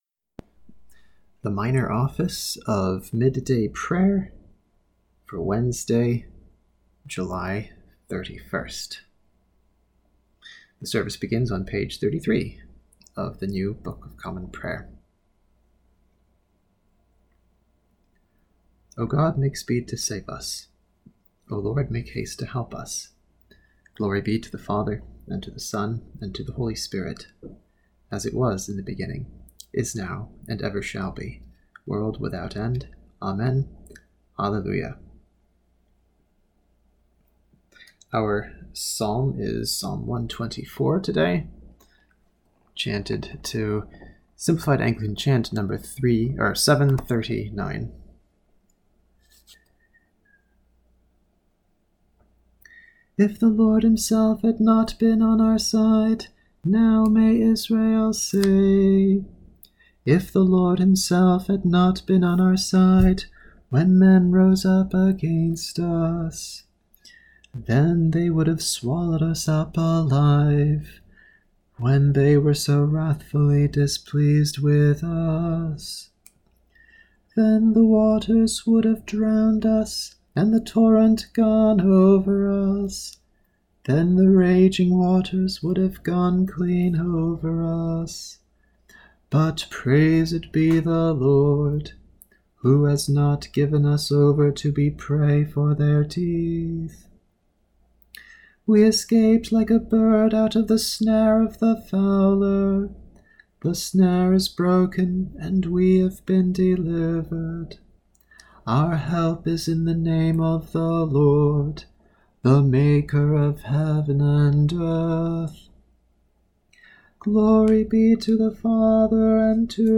Let’s pray Midday Prayer together!
Here’s today’s service in under 12 minutes: